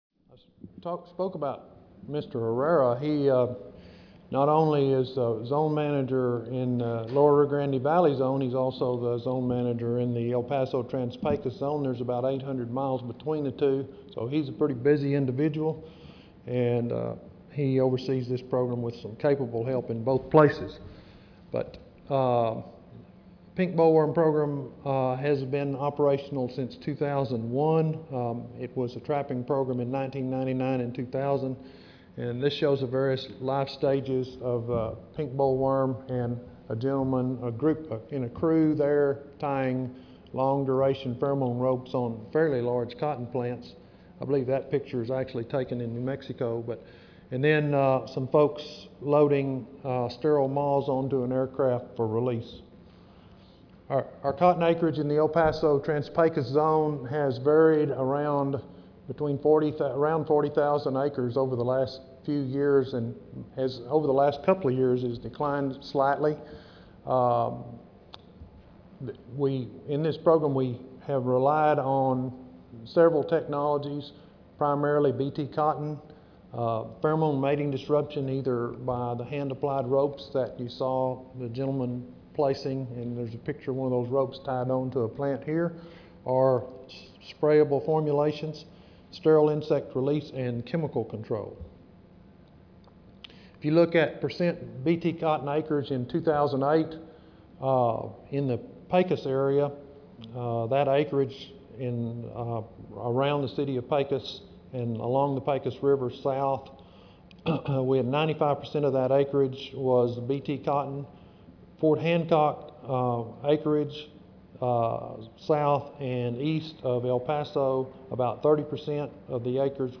Salon I (Marriott Rivercenter Hotel)
Recorded presentation